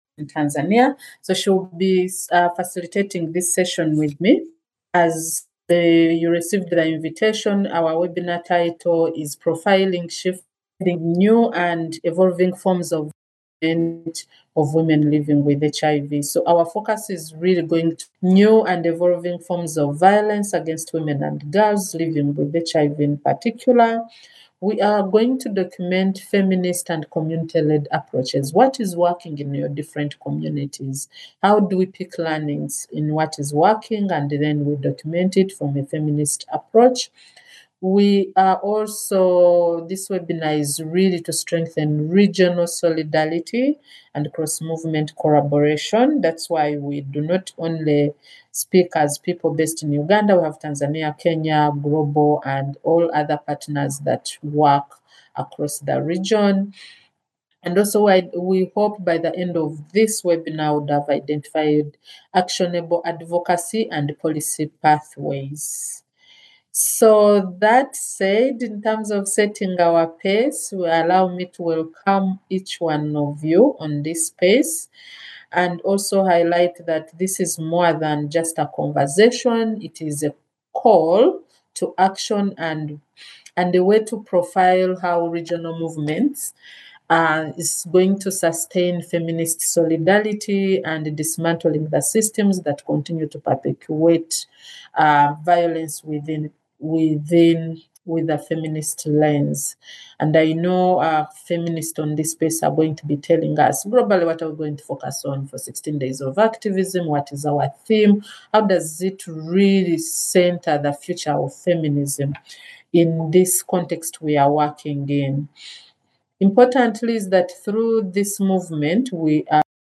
Webinar: Shifts in Ending New and Evolving Forms of Violence Part 1 – International Community of Women living with HIV Eastern Africa